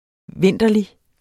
Udtale [ ˈvenˀdʌli ]